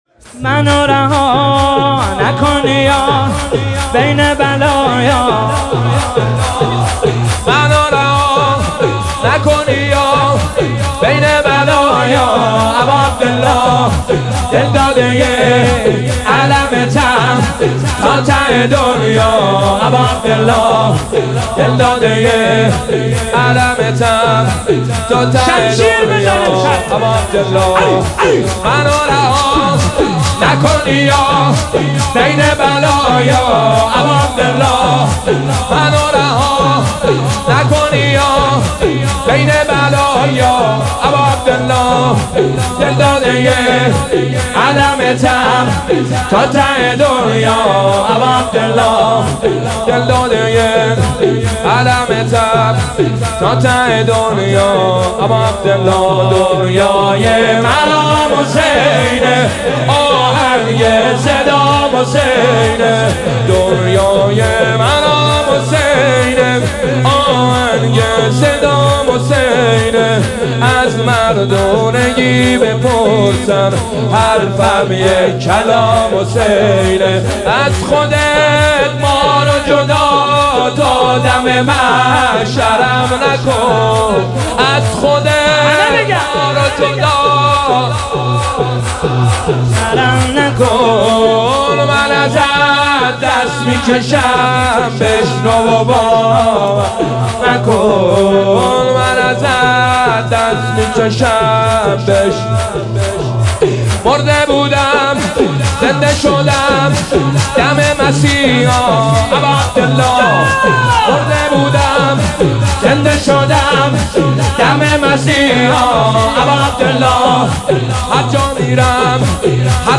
شور زیبا